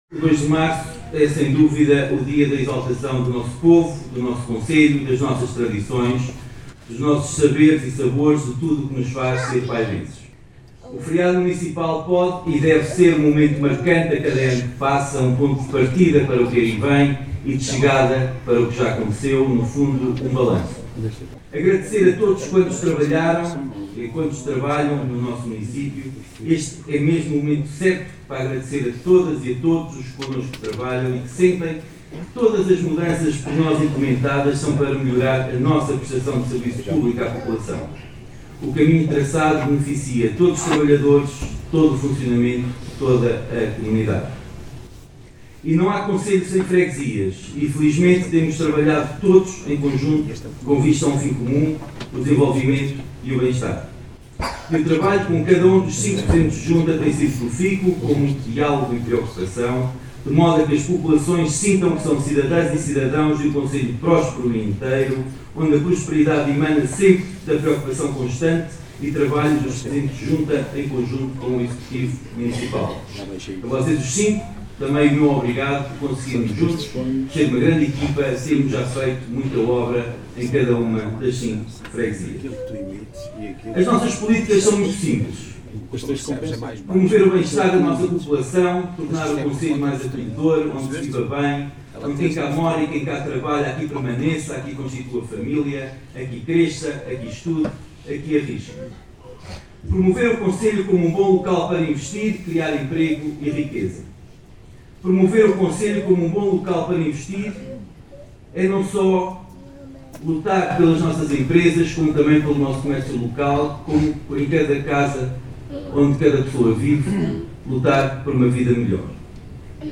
Paulo Marques, Presidente do Município de Vila Nova de Paiva, no seu discurso, falou da importância de todos trabalharem em conjunto em prol do desenvolvimento e da promoção do concelho, em todas as suas vertentes.
P.Marques-Discurso-2-marco-2026.mp3